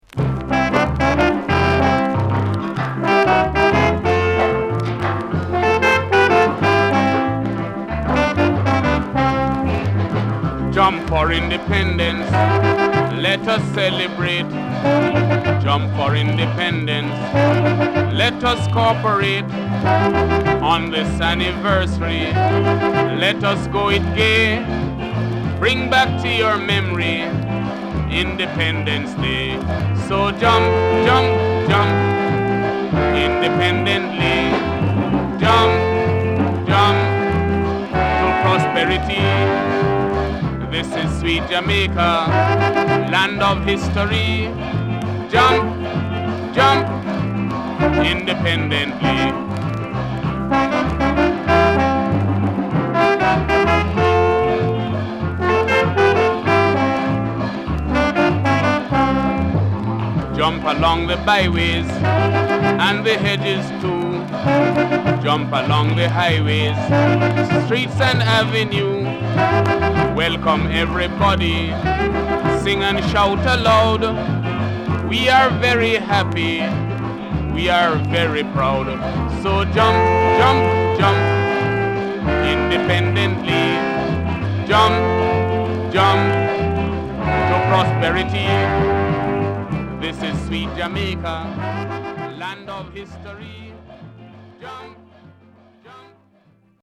W-Side Good Calypso
SIDE A:全体的にチリノイズがあり、少しプチノイズ入ります。